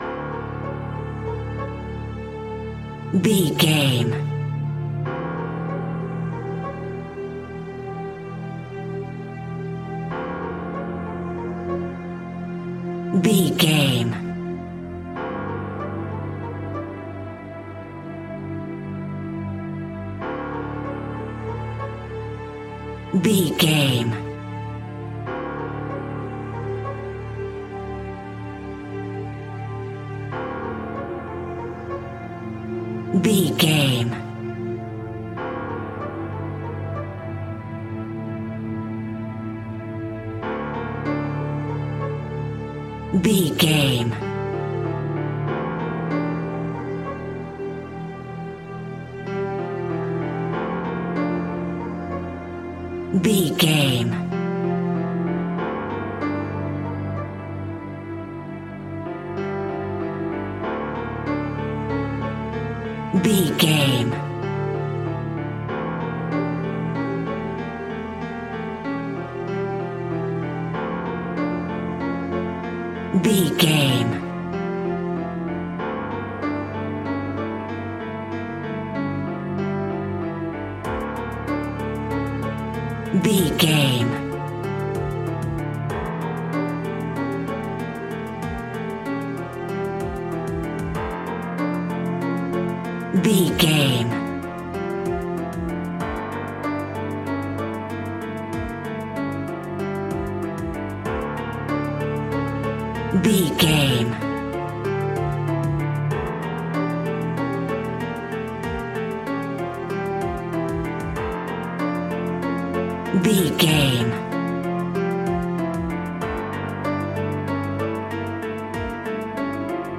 In-crescendo
Aeolian/Minor
scary
ominous
dark
suspense
eerie
piano
strings
percussion
cello
synth
pads